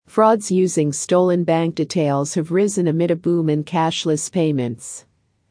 【ややスロー・スピード】
答案の分析が済んだら、ネイティブ音声を完全にコピーするつもりで音読を反復してくださいね。